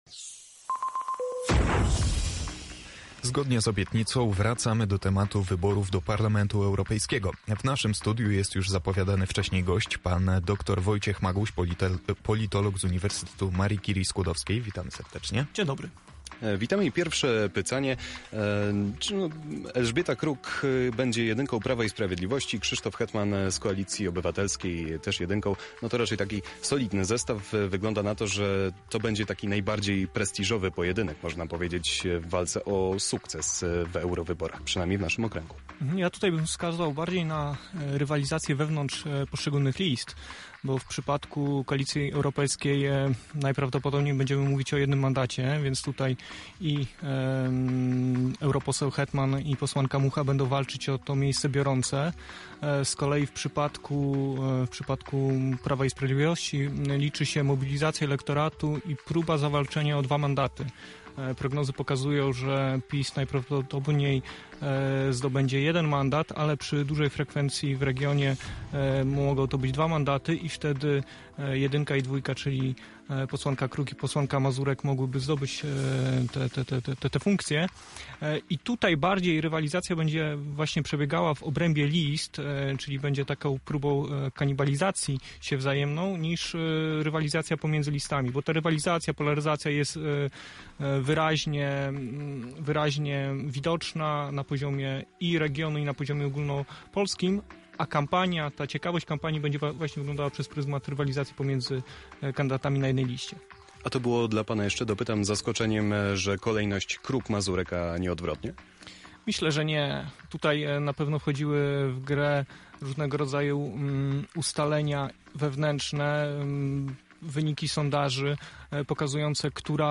Kampania przed Eurowyborami ruszyła pełną parą – komentarz